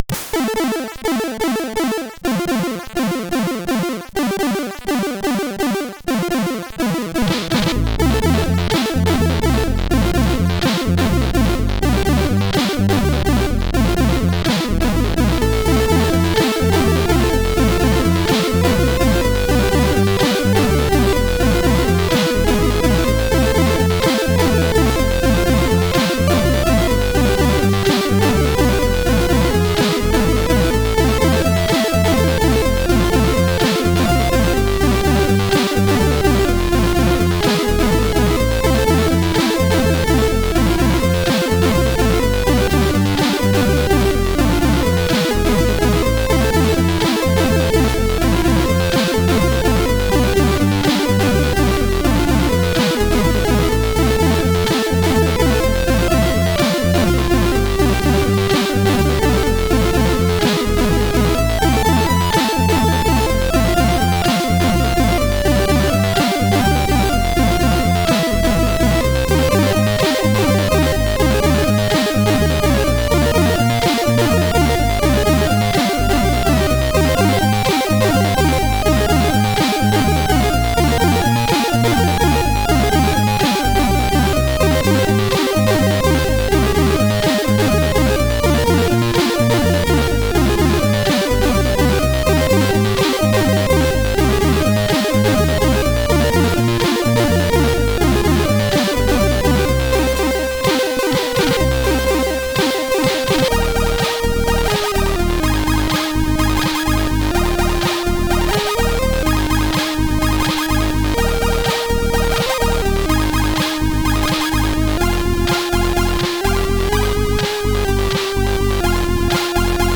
Commodore SID Music File
1 channel